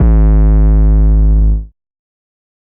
808 [ Old Bool ].wav